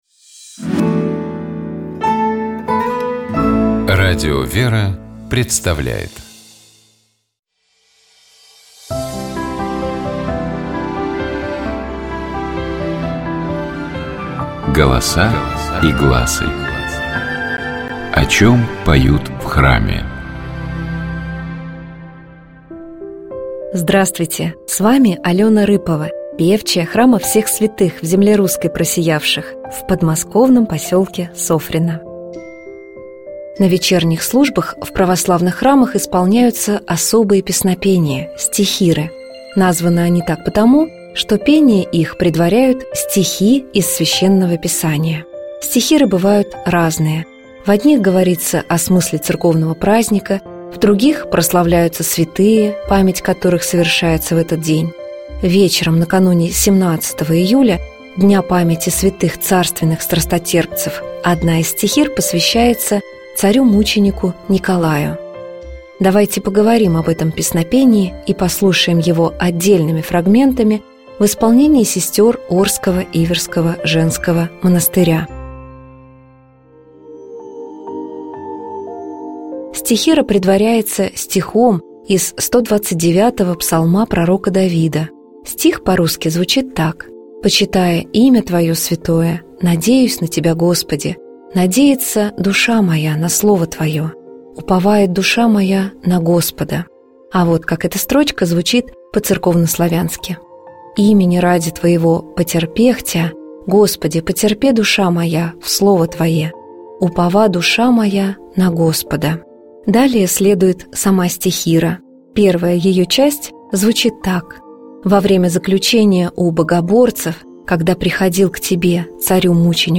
Давайте поговорим об этом песнопении и послушаем его отдельными фрагментами в исполнении сестёр Орского Иверского женского монастыря.
Давайте послушаем стихиру, посвящённую царю-мученику Николаю полностью в исполнении сестёр Орского Иверского женского монастыря.